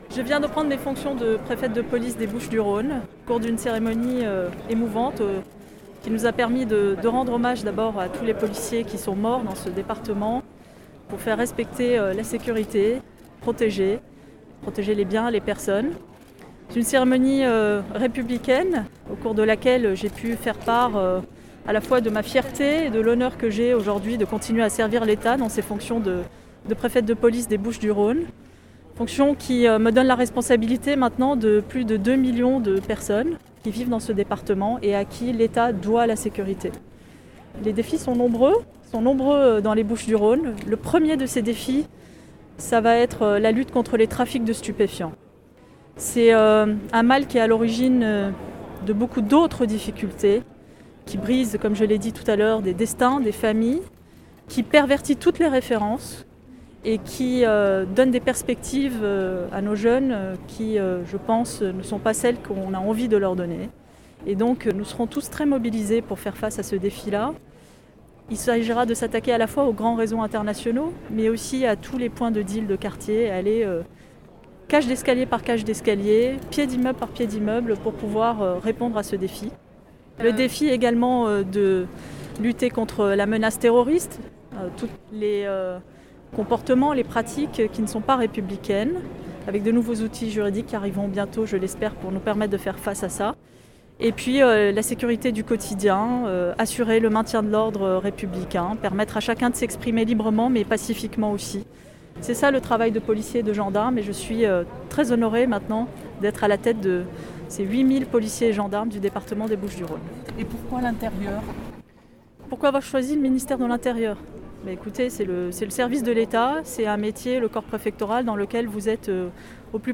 A l’issue d’une cérémonie de prise de fonction dans les formes, avec un dépôt de gerbe au monument des policiers morts du département des Bouches-du-Rhône, un passage en revue des troupes, discours… la nouvelle préfète de police des Bouches du Rhône a rencontré la presse.